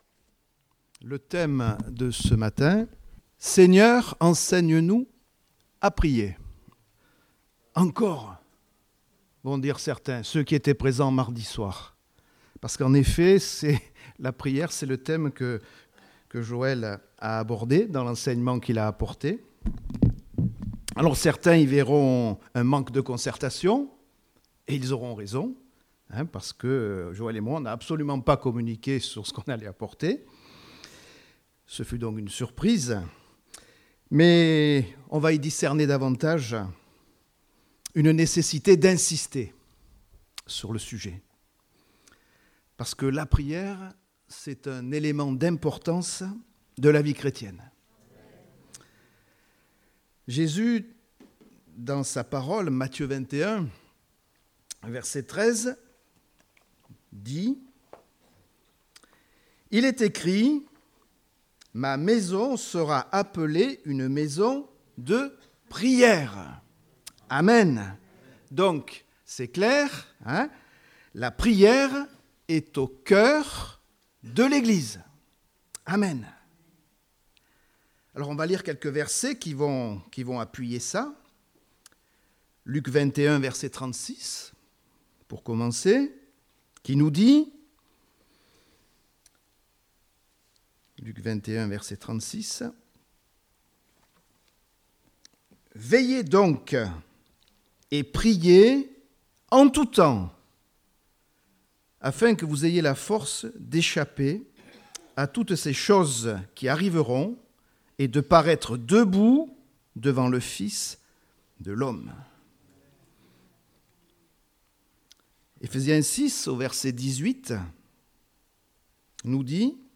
Date : 24 mars 2019 (Culte Dominical)